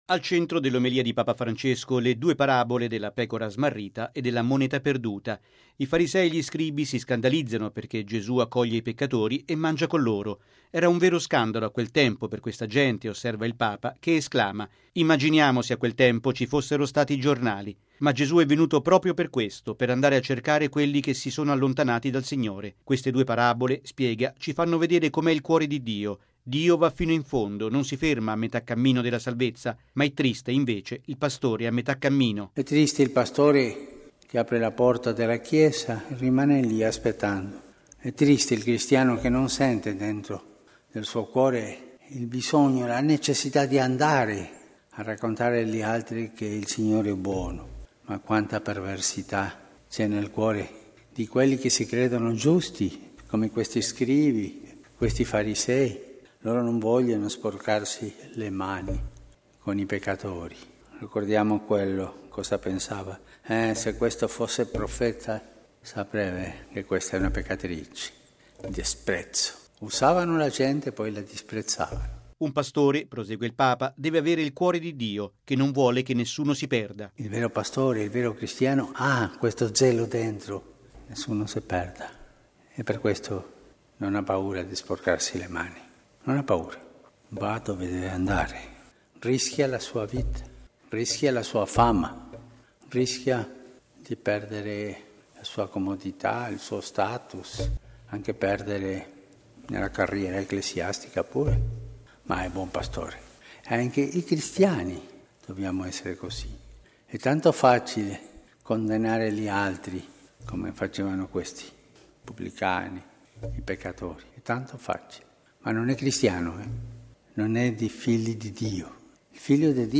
Al centro dell’omelia di Papa Francesco, le due parabole della pecora smarrita e della moneta perduta.